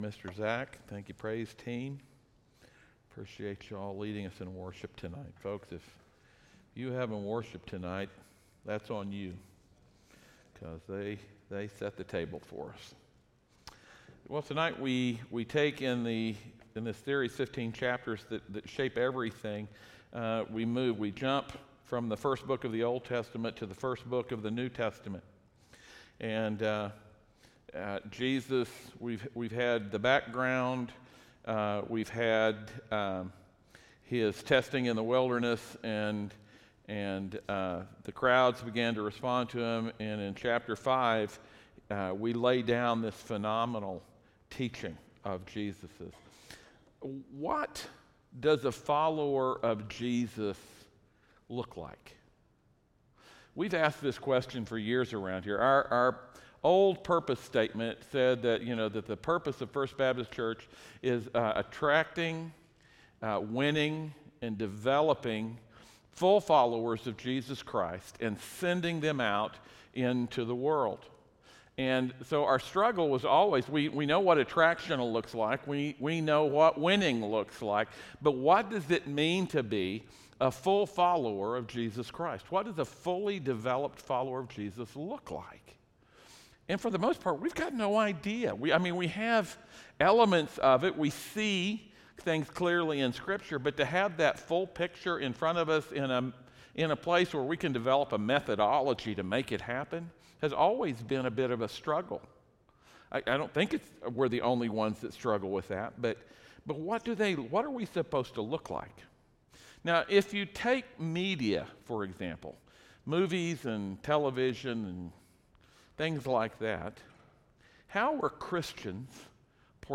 Passage: Matthew 5 Service Type: audio sermons